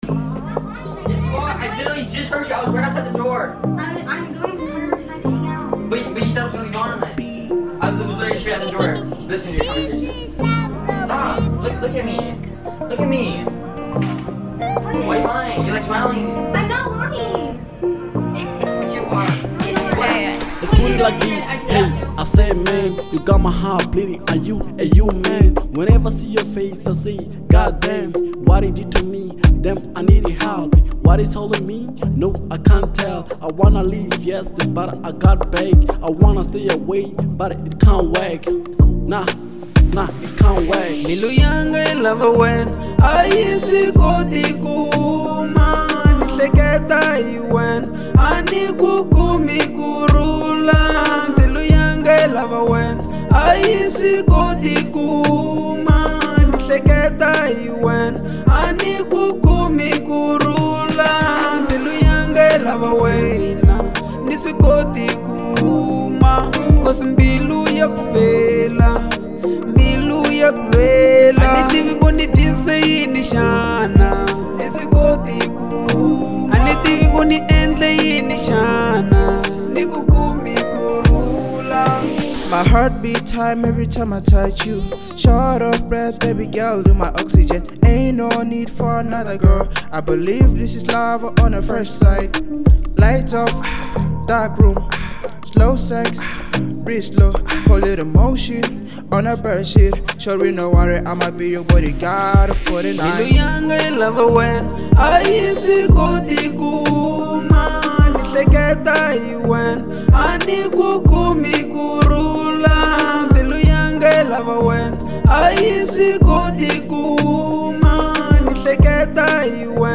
02:45 Genre : Afro Pop Size